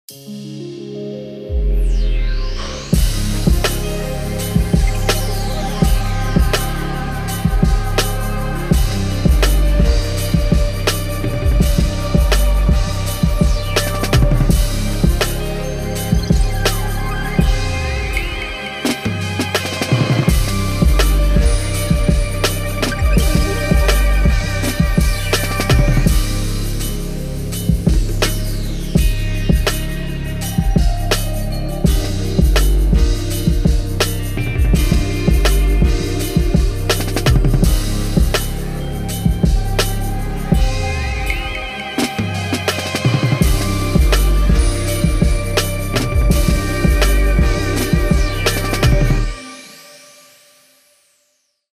Premium hip hop beats